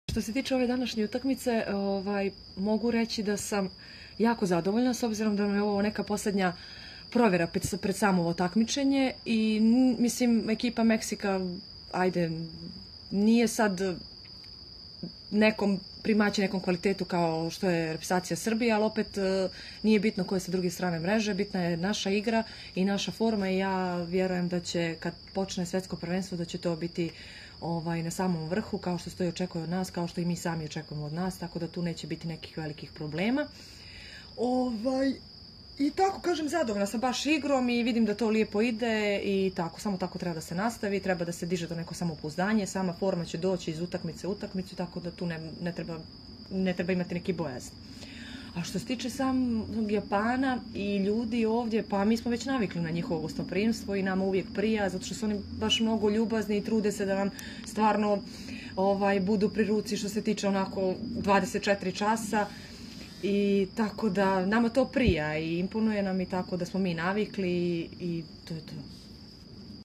Izjava Silvije Popović